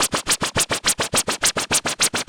Index of /musicradar/rhythmic-inspiration-samples/105bpm
RI_RhythNoise_105-02.wav